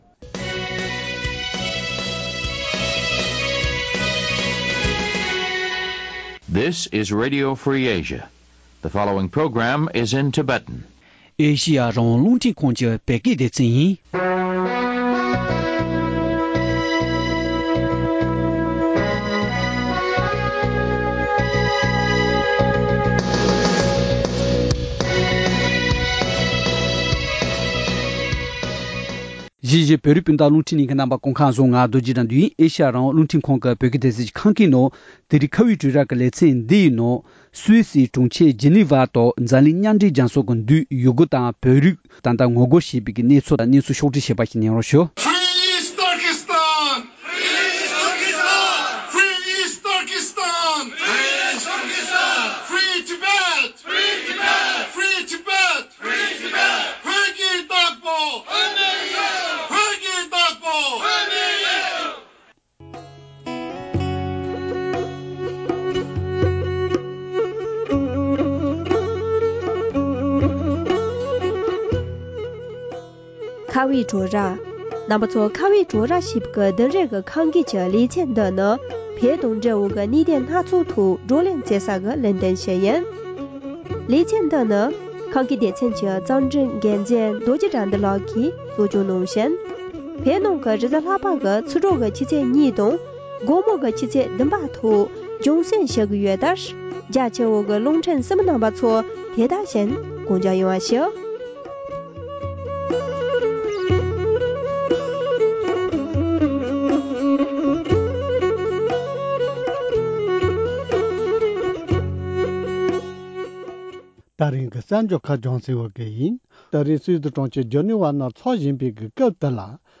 འབྲེལ་ཡོད་མི་སྣ་ཁག་ལ་བཅར་འདྲི་ཞུས་པར་གསན་རོགས་ཞུ༎